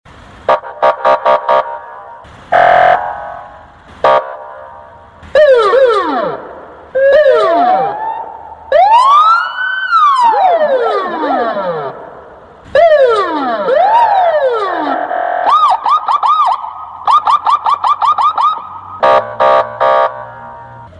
Сирена ДПС звук крякалки